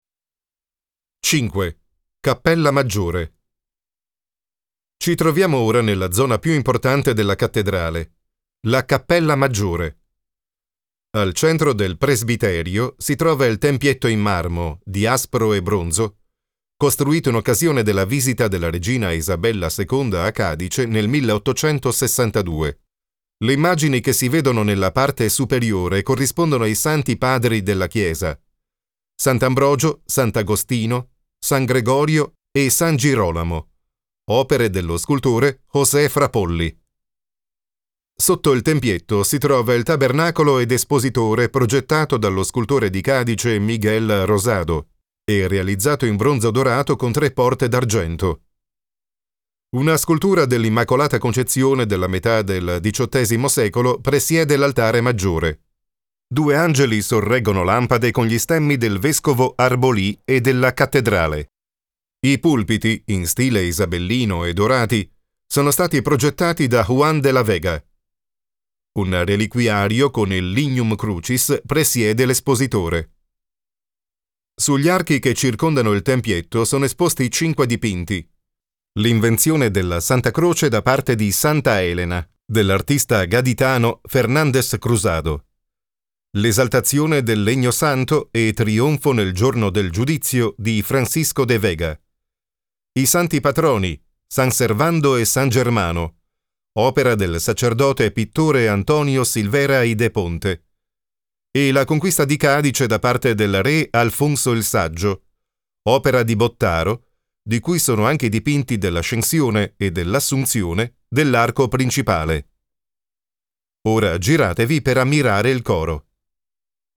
Commercieel, Speels, Volwassen, Warm, Zakelijk
Audiogids